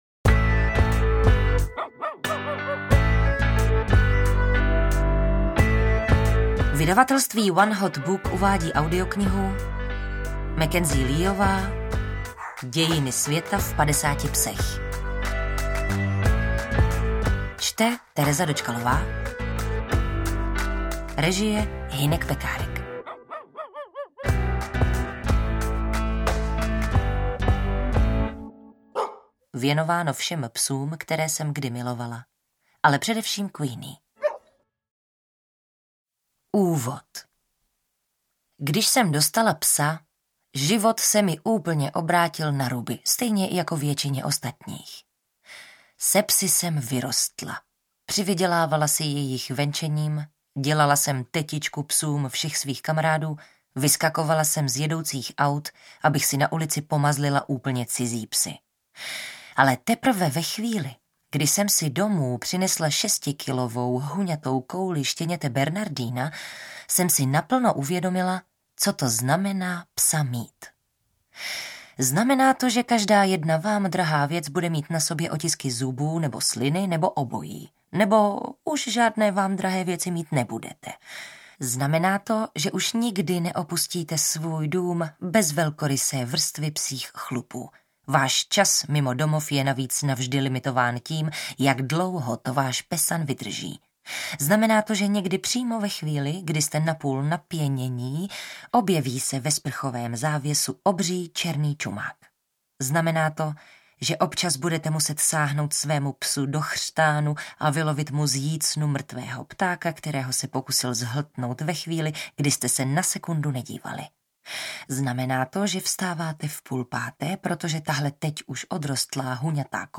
AudioKniha ke stažení, 51 x mp3, délka 6 hod. 28 min., velikost 338,0 MB, česky